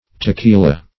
Tequila \Te*qui"la\